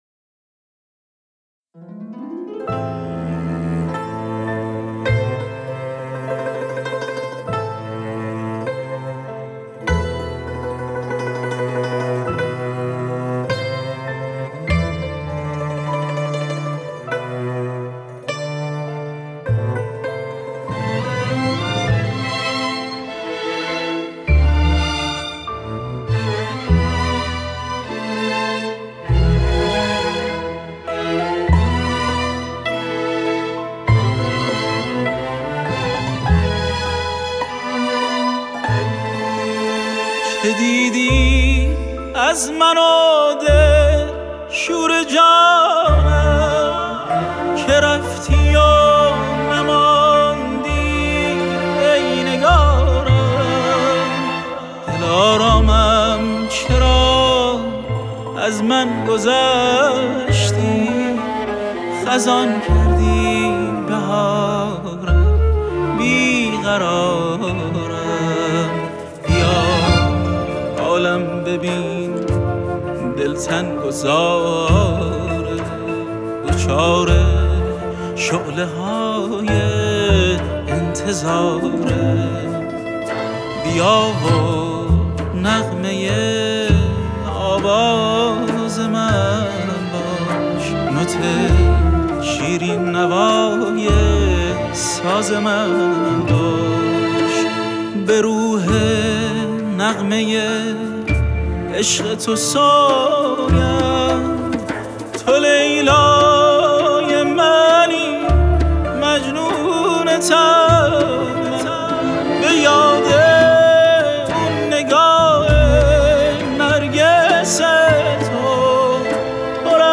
سنتور
فلوت آلتو
ویولن سولو
ویولن ارکستر :
ویولن آلتو ارکستر :
ویولنسل
کنترباس
استودیو مدرن